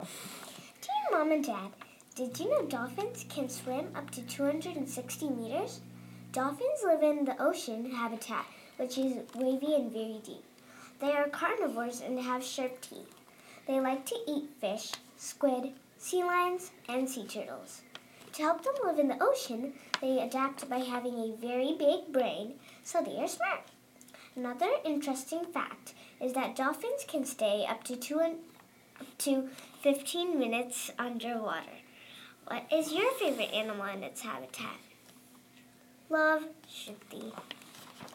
Dolphin